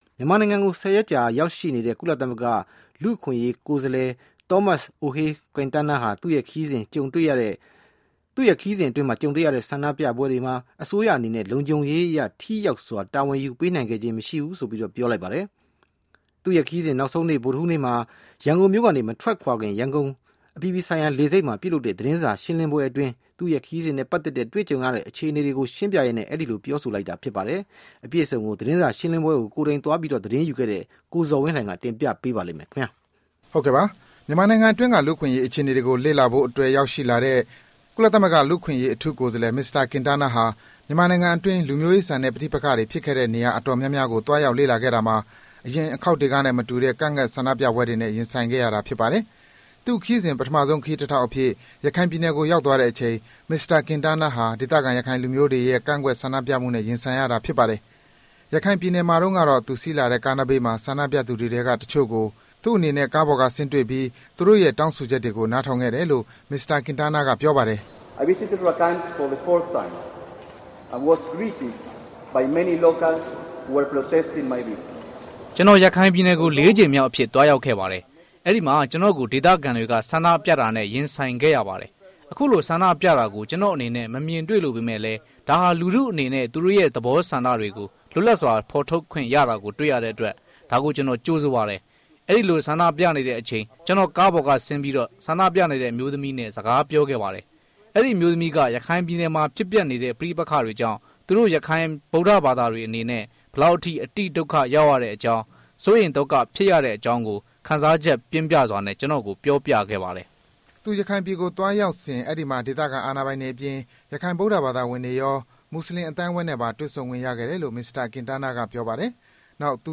ကင်တားနားသတင်းစာရှင်းလင်းပွဲ